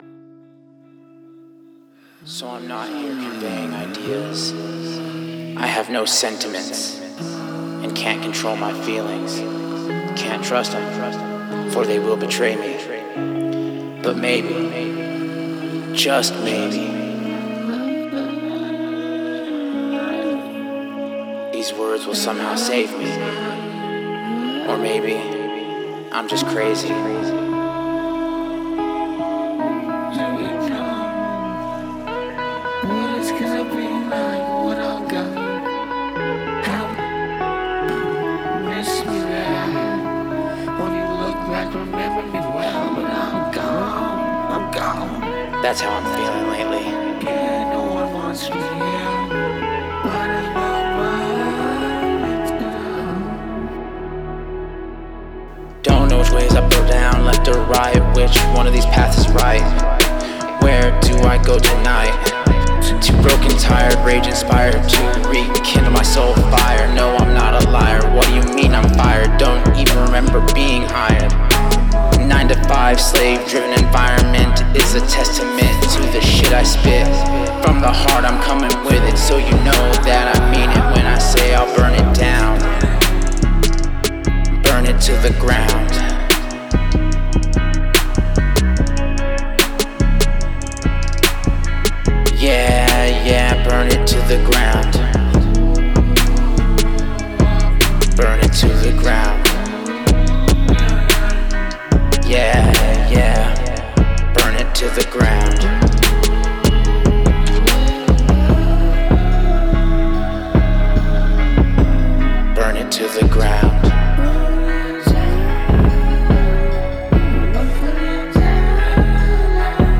Date: 2026-05-03 · Mood: dark · Tempo: 91 BPM · Key: D major